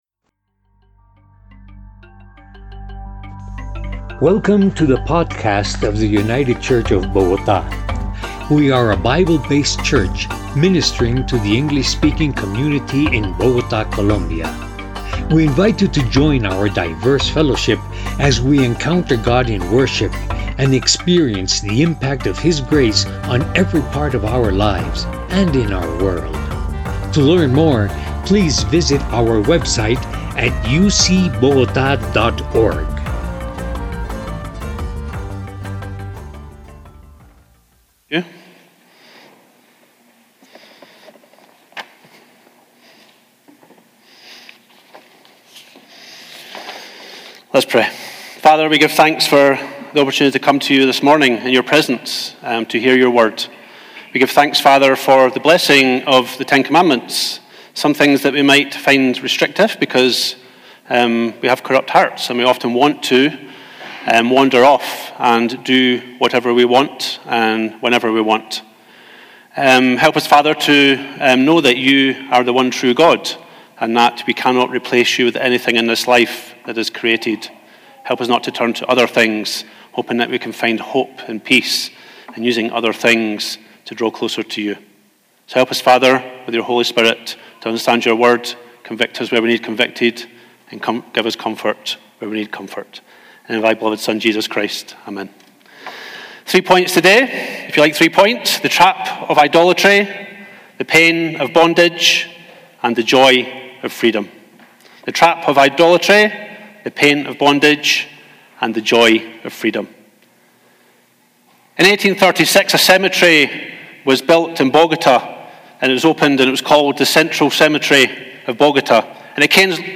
Categories: Sermons